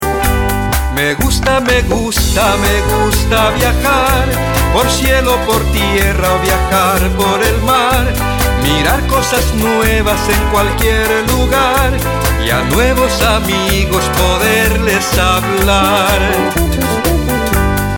Esta hermosa canción infantil en español